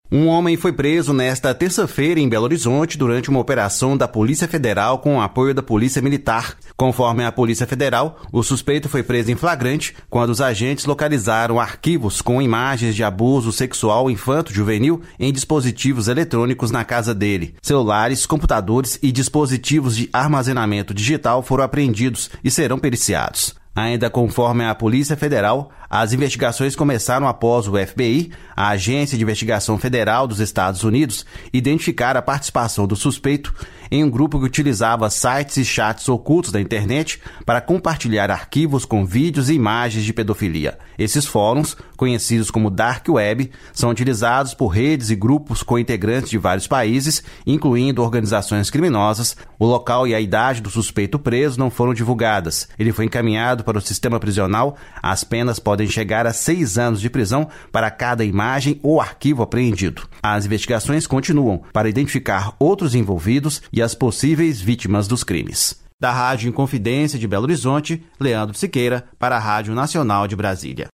* Com reportagem da Rádio Educadora de Salvador.